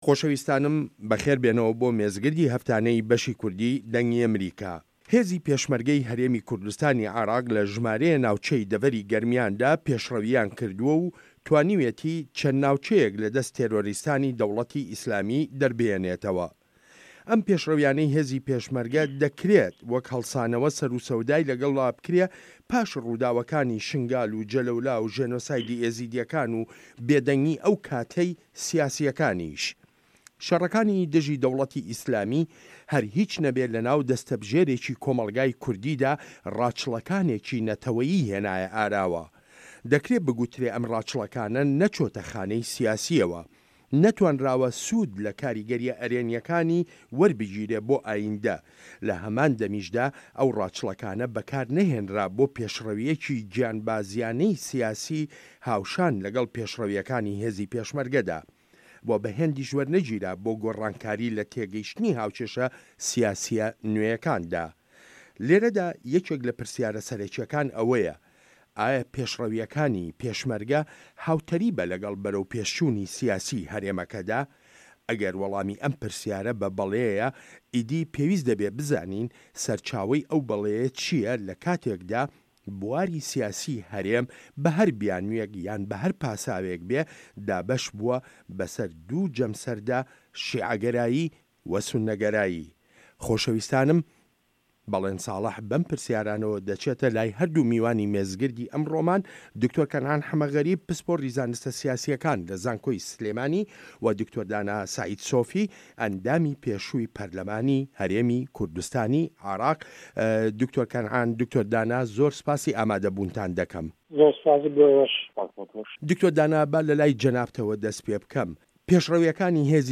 مێزگرد : پێشڕه‌ویه‌کانی پێشمه‌رگه‌ و ڕه‌هه‌ندی سیاسی له‌ هه‌رێم